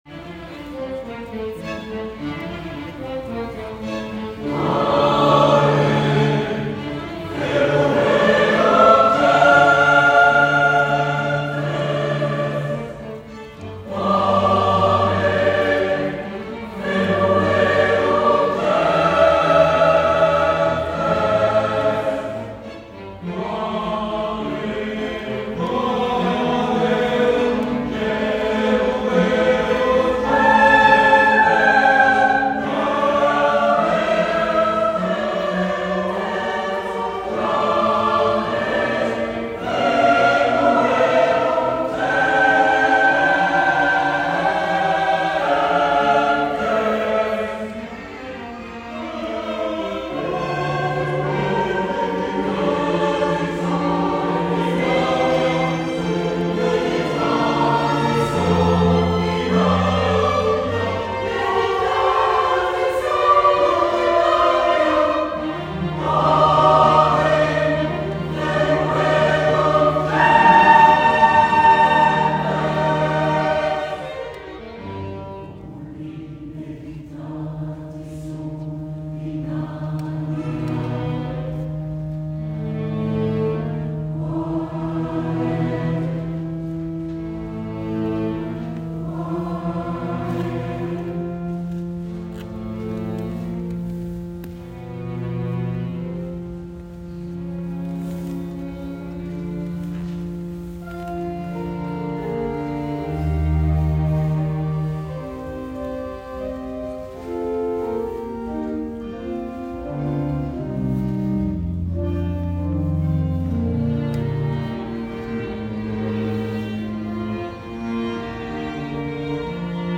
Quelques Extraits audio de notre concert à l’église Saint Vincent au Mesnil le Roi le dimanche 14 Janvier 2024.